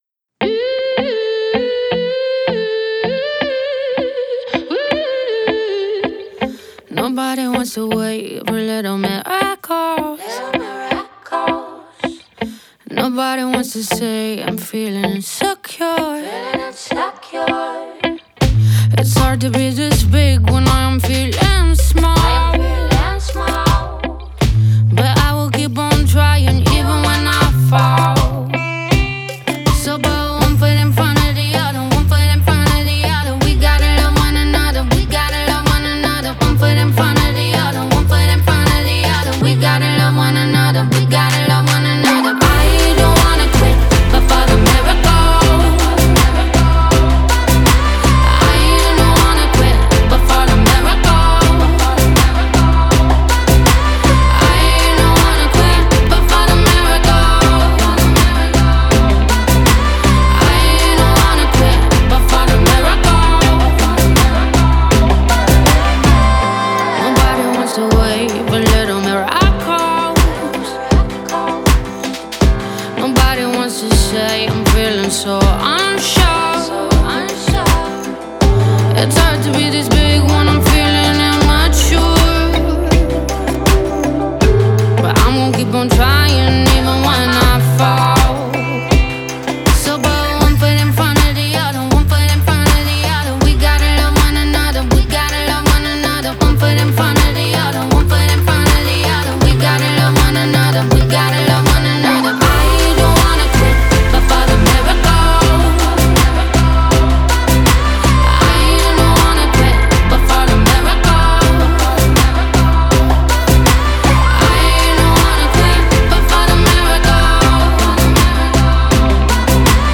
мощная поп-баллада
характерный сильный вокал